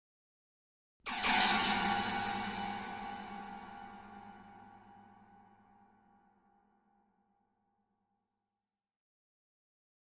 Sharp Horror Chord 1